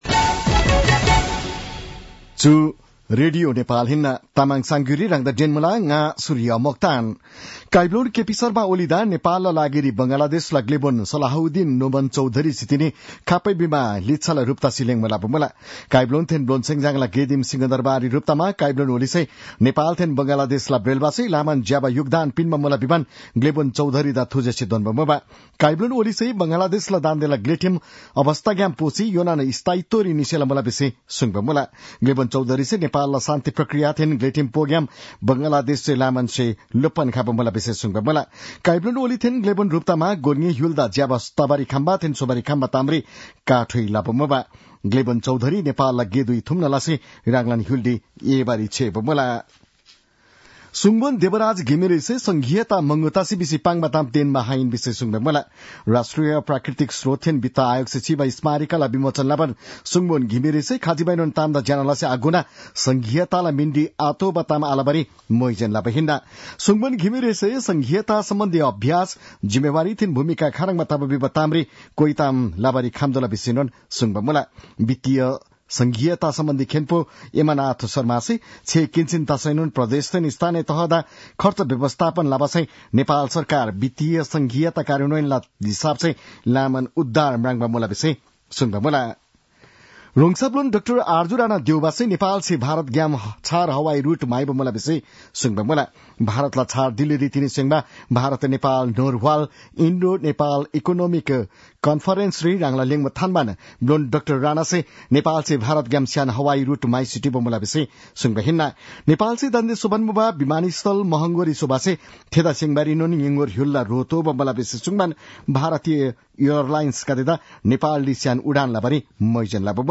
तामाङ भाषाको समाचार : ६ पुष , २०८१
Tamang-news-9-05.mp3